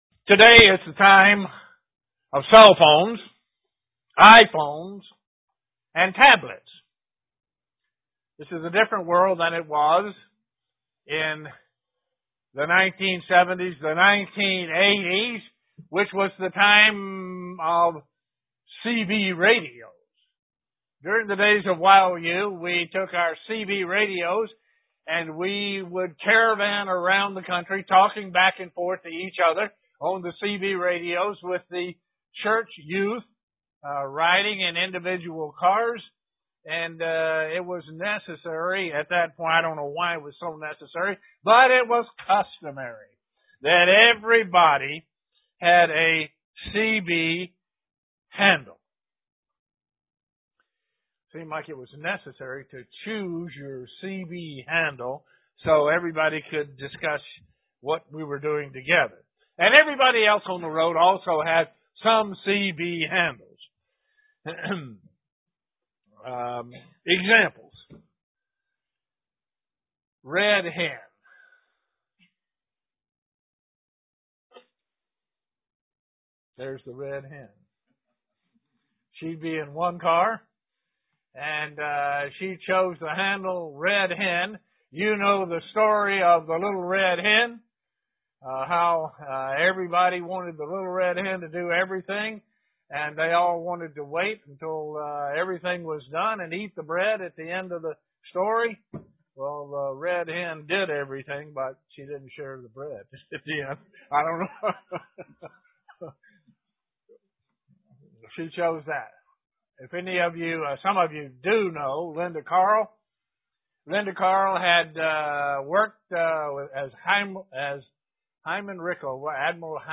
Print The correlation of the blessed are the peacemakers beatitude to the Feast of Tabernacles UCG Sermon Studying the bible?